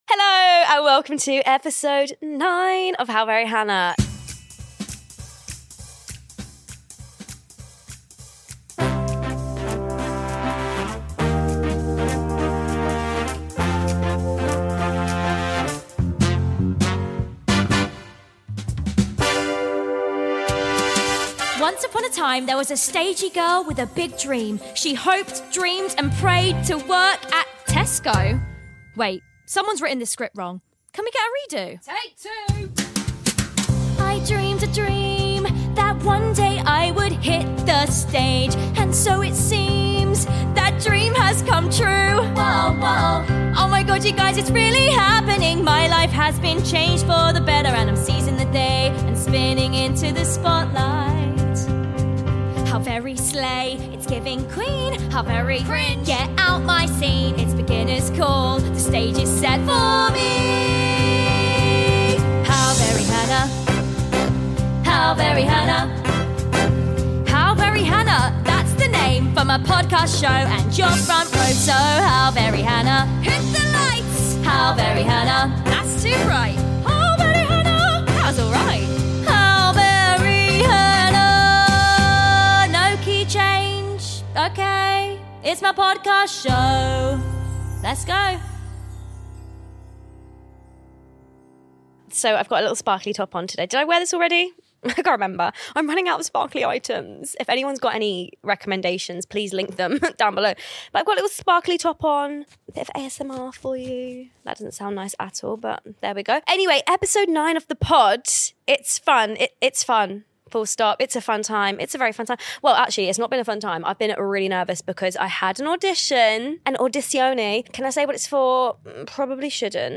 It’s a solo episode... or is it?